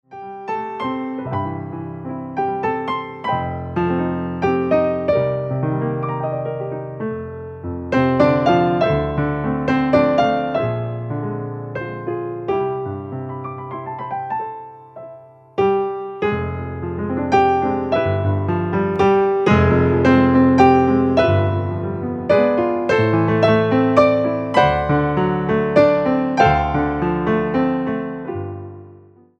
entertainment pianists